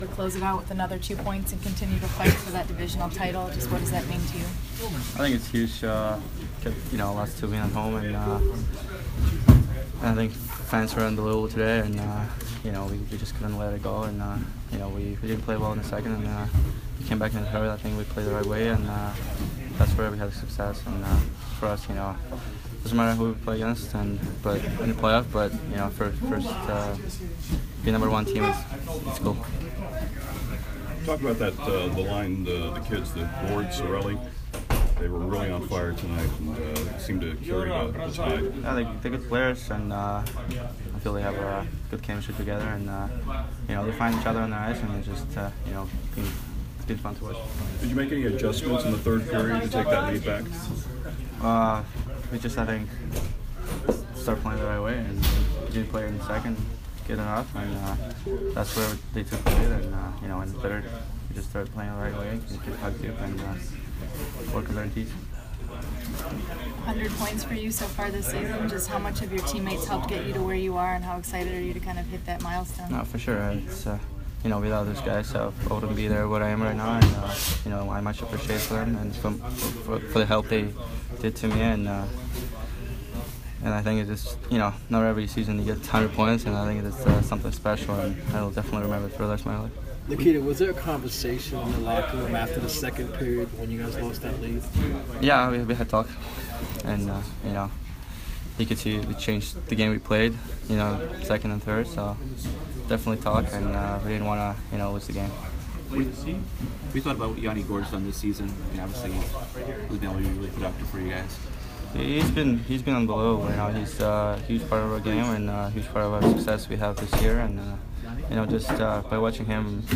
Nikita Kucherov post-game 4/6